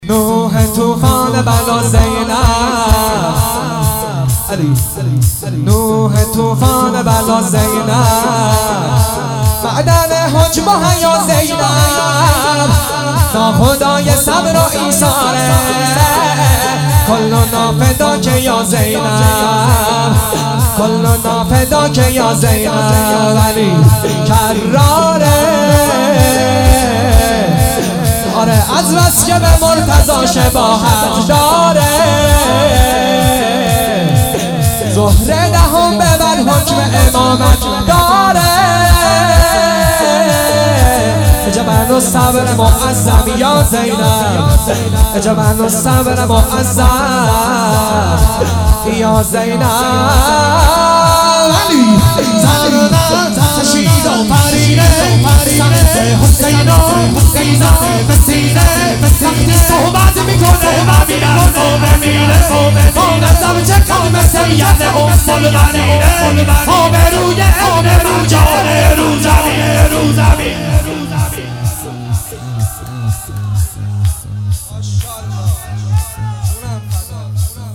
شور نوح طوفان بلا زینب